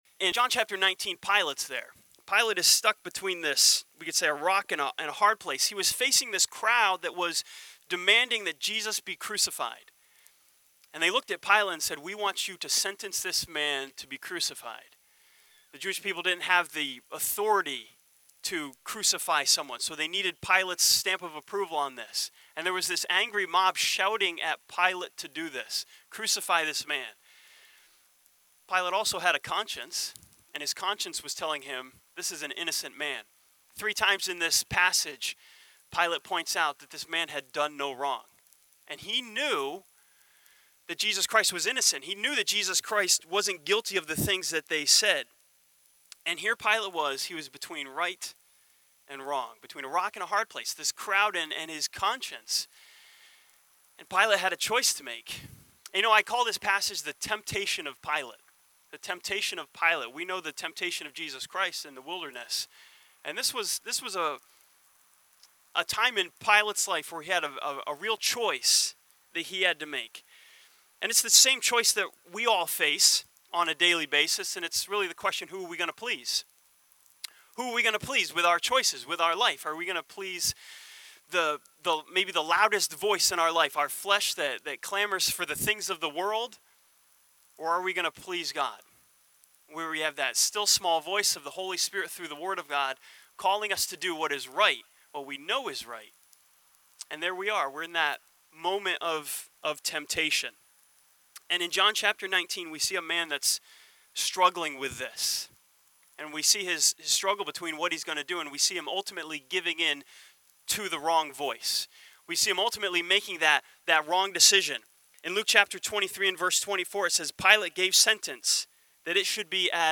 This sermon from John chapter 19 looks at the temptation of Pilate and learns from his first steps toward crucifying Jesus.